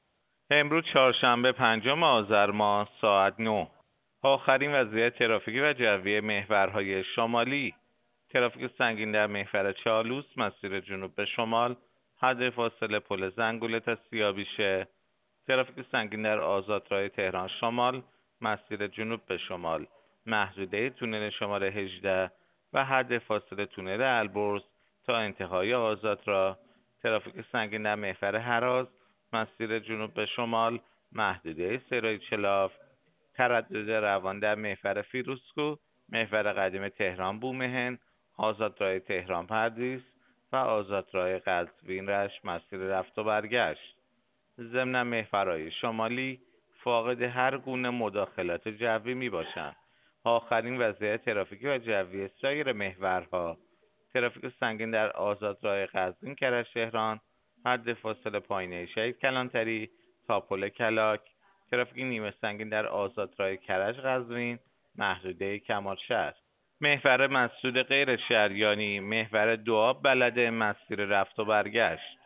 گزارش رادیو اینترنتی از آخرین وضعیت ترافیکی جاده‌ها ساعت ۹ پنجم آذر؛